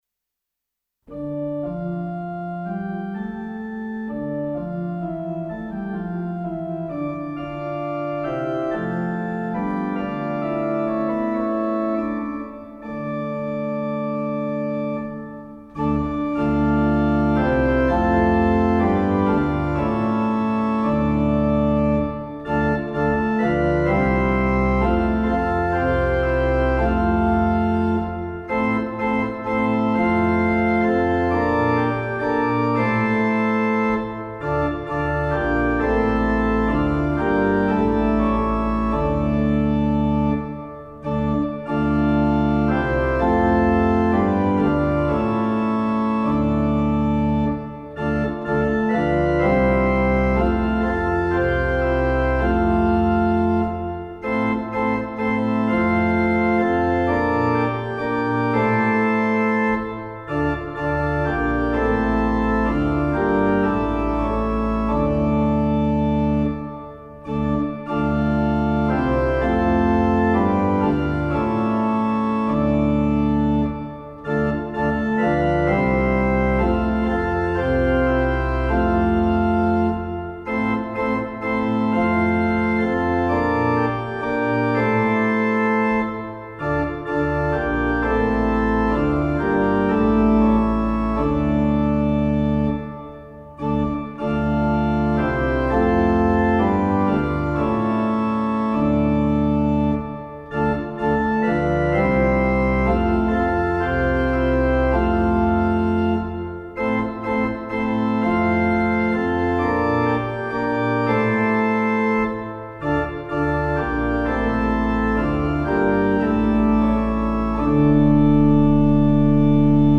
Je vous invite donc à chanter au numéro 31/12 dans Alléluia